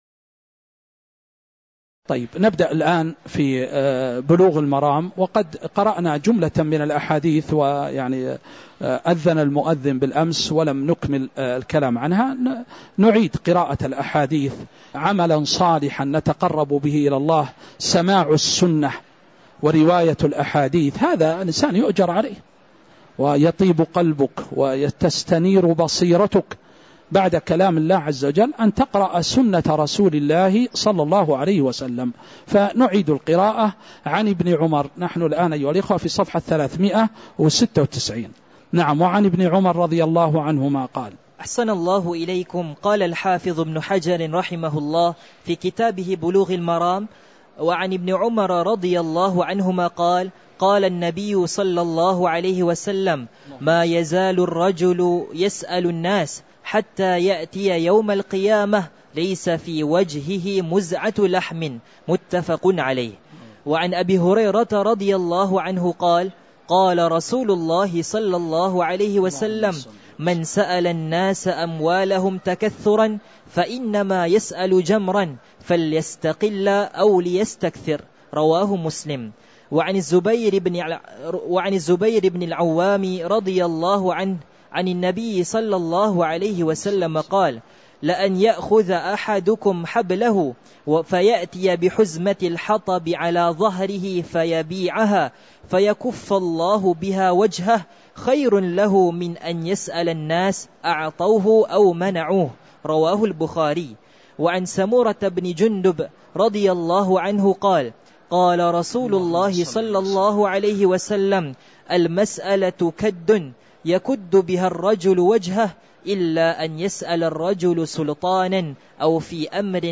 تاريخ النشر ١٩ شوال ١٤٤٥ هـ المكان: المسجد النبوي الشيخ